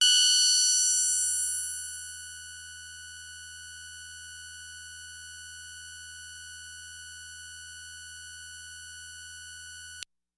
标签： F6 MIDI音符-90 Oberheim-Xpander的 合成器 单票据 多重采样
声道立体声